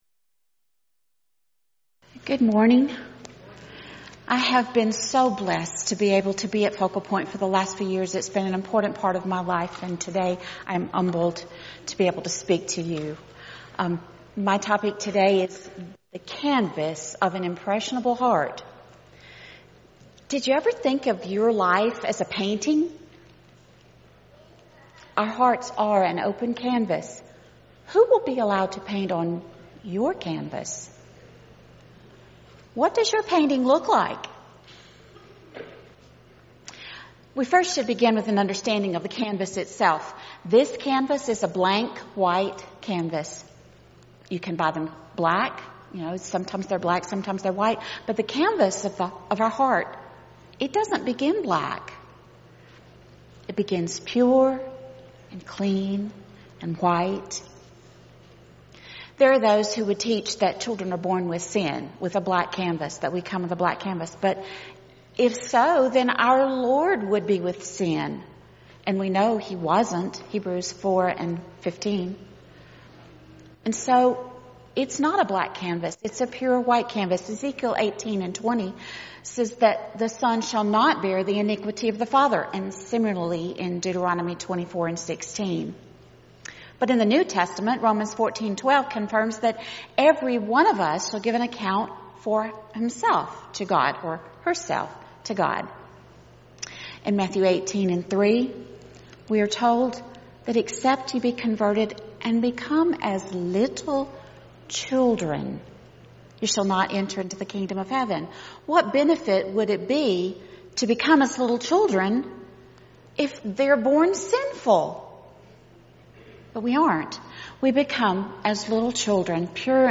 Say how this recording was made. Event: 2019 Focal Point